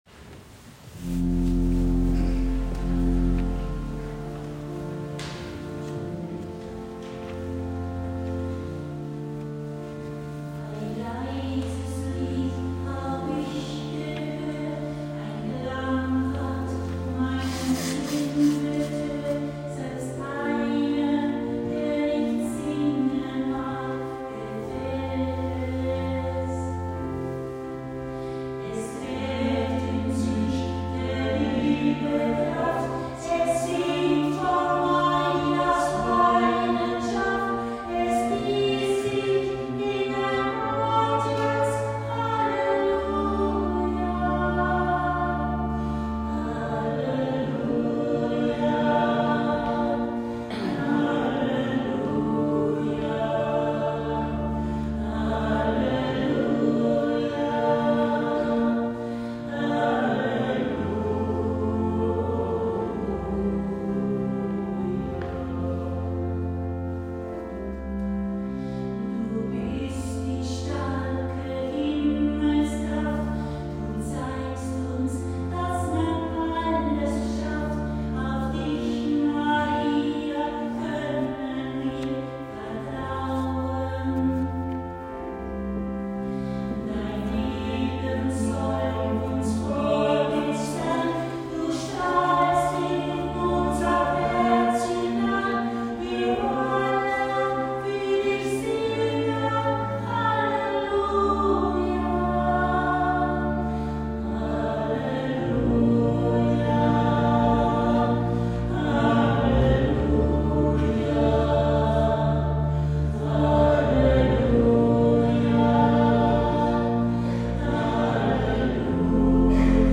Witterungsbedingt musste die eigentlich an der Marienkapelle geplante Andacht in die Pfarrkirche Tegernheim verlegt werden.
In hervorragender Weise hat der Familiengesang Kreiml diese Bezirksmaiandacht musikalisch begleitet.